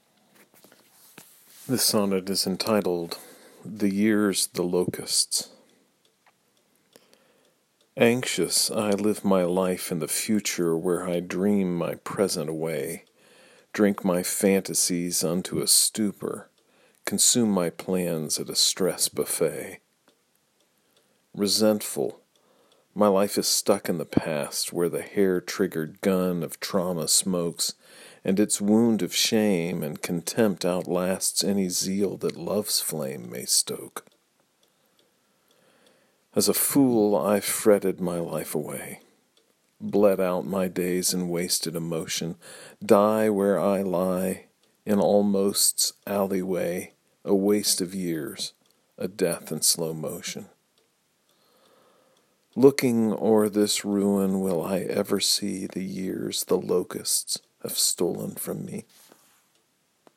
If it’s helpful, you may listen to me read the sonnet via the player below.